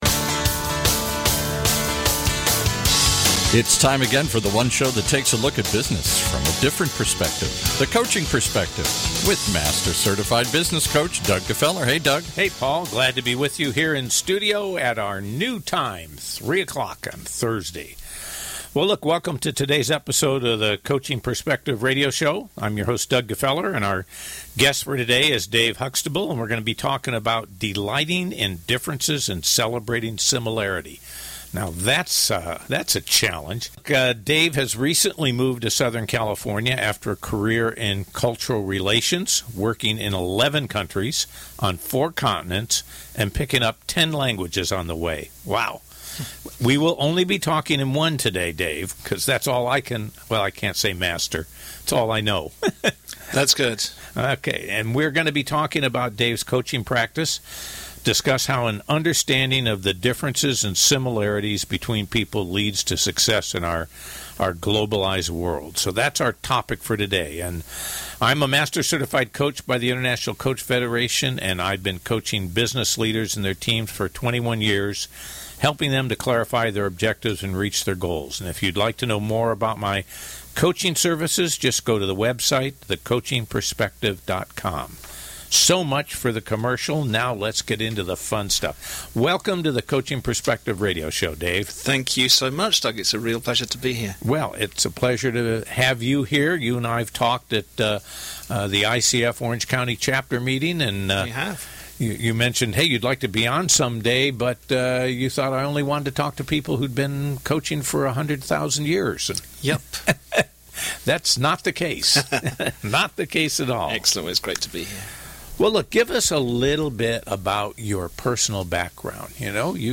Recorded live on July 13, 2017 on The Coaching Perspective Radio Show.